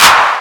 Index of /90_sSampleCDs/Techno_Trance_Essentials/DRUMS/CLAP
40_10_clap.wav